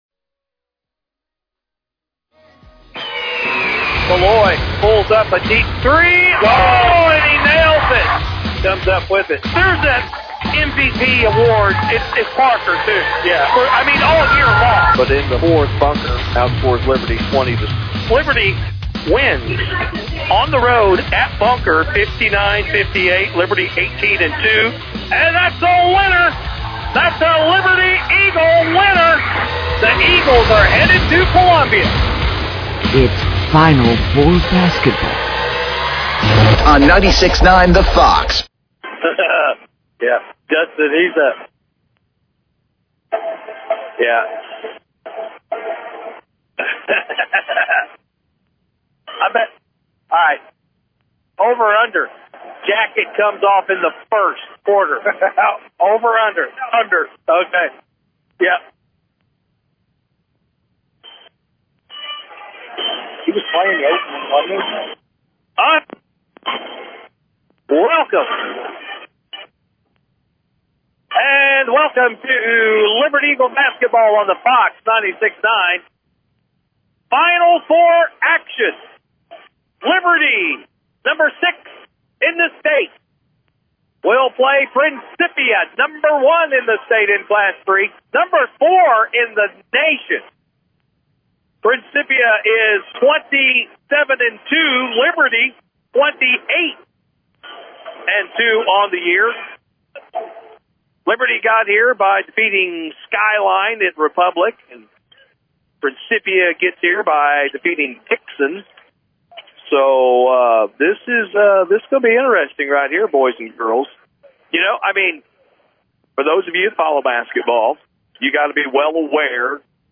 The Liberty Eagles traveled to Mizzou Arena, Site of The Show Me Showdown where they faced the 27-2 Principia Panthers for Semifinal Action in The Final Four.